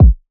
{Kick} outside.wav